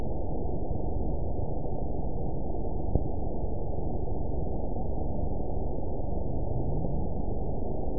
event 922749 date 03/24/25 time 23:32:50 GMT (2 months, 3 weeks ago) score 5.61 location TSS-AB01 detected by nrw target species NRW annotations +NRW Spectrogram: Frequency (kHz) vs. Time (s) audio not available .wav